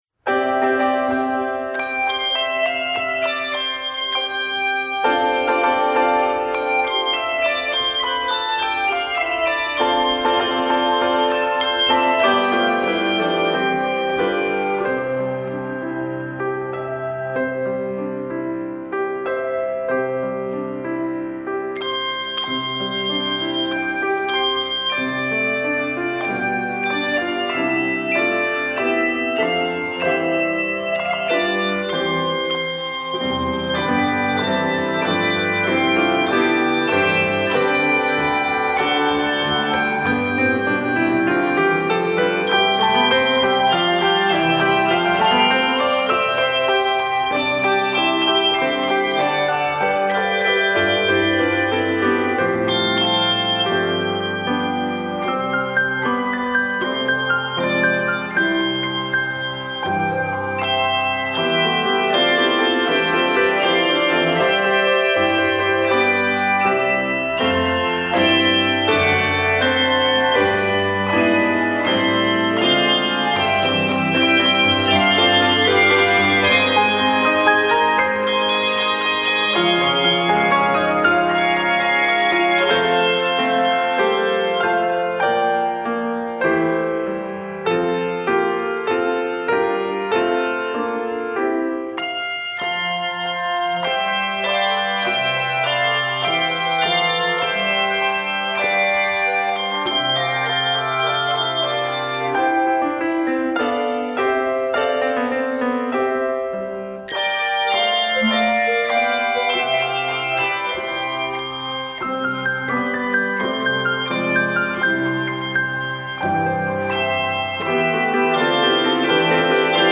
handbells and piano
Keys of C Major and F Major.